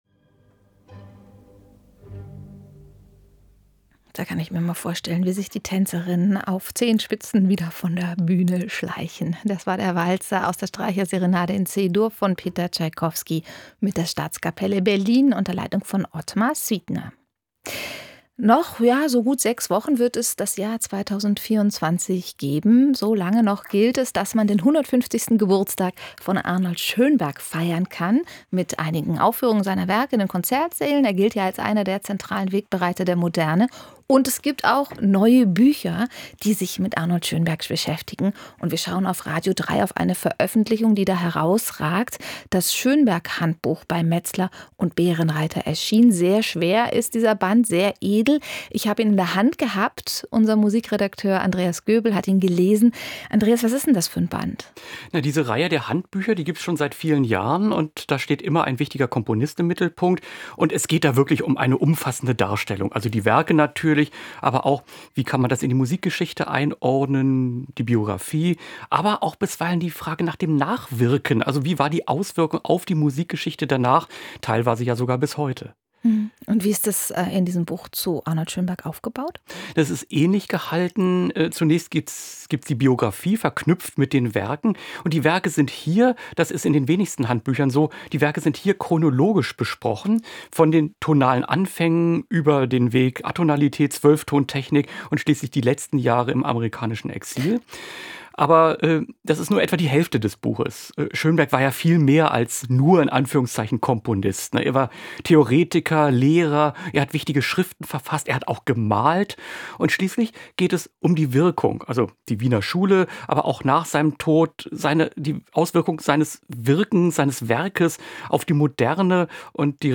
Buchkritik